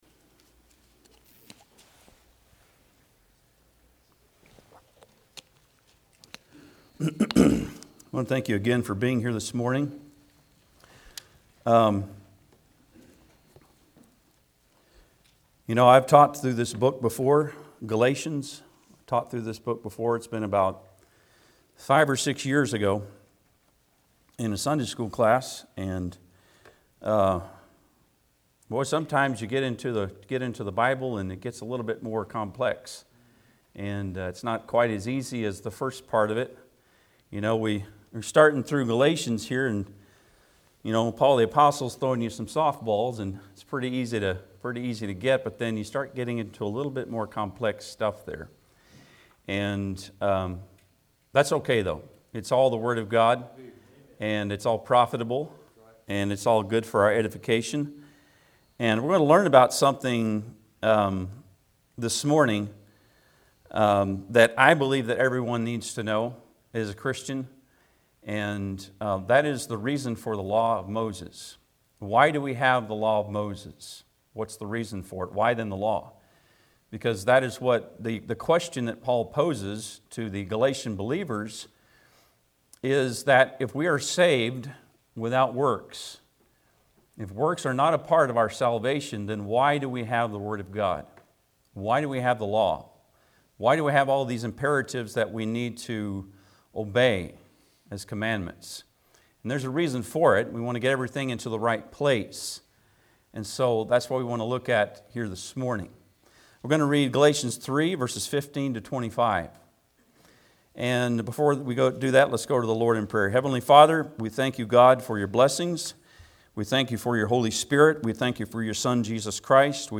Galatians 3:15-25 Service Type: Sunday am Bible Text